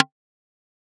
• インパルス応答